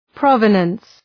Προφορά
{‘prɒvənəns}